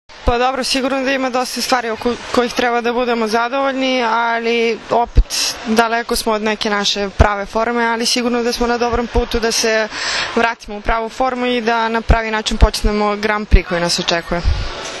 IZJAVA SUZANE ĆEBIĆ